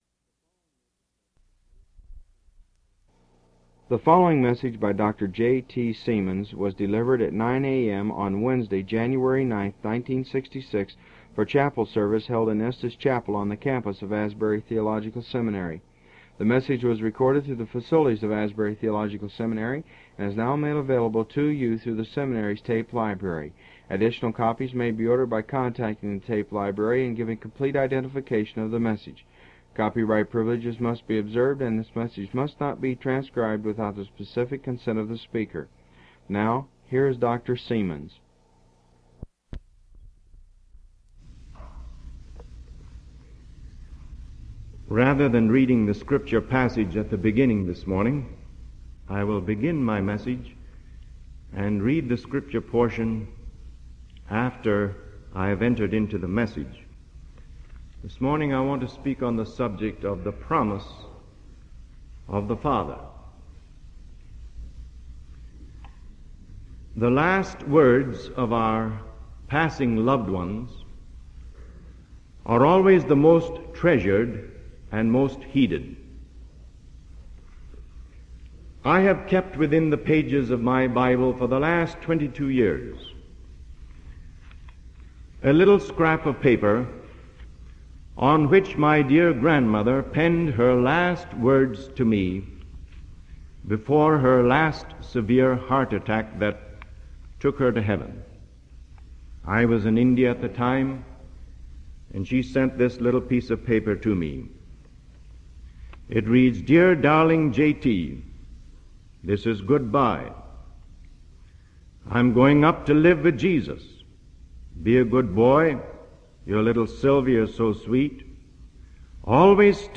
Faculty chapel services, 1966